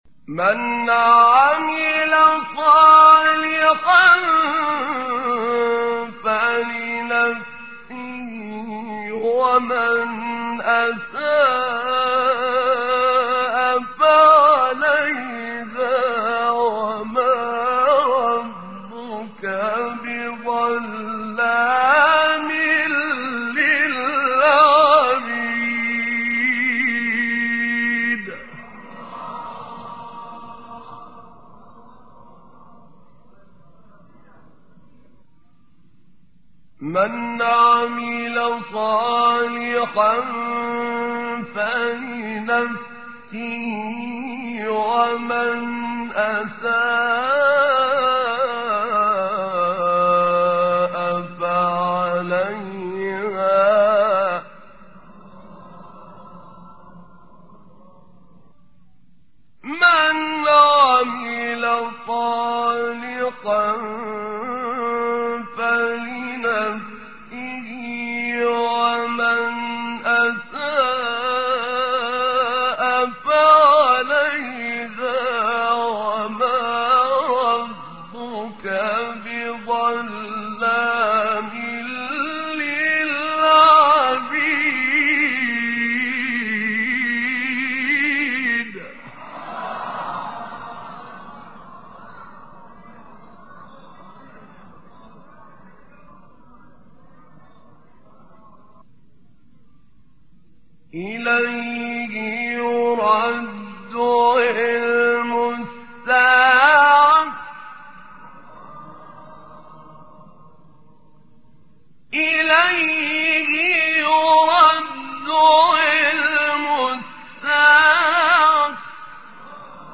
مقام حجاز شیخ طاروطی | نغمات قرآن | دانلود تلاوت قرآن